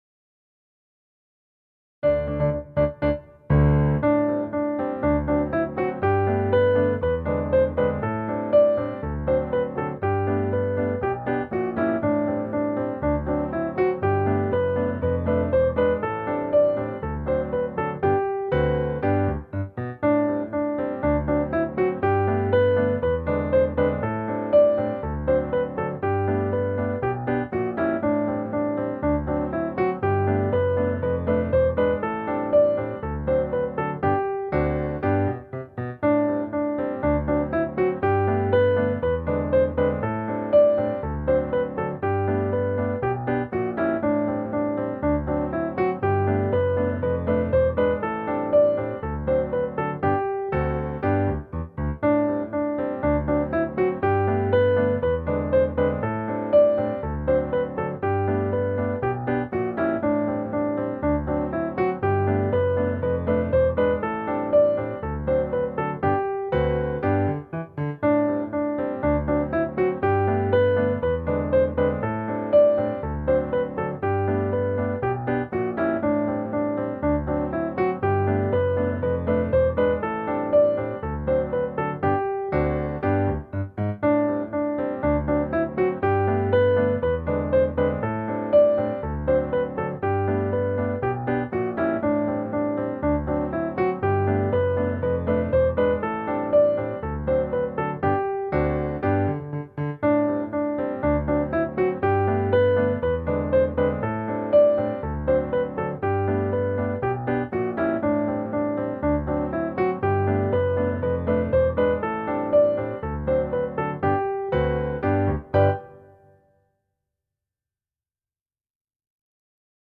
melodia ludowa
akompaniament